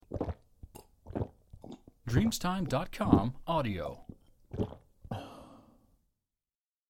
Trinkwasser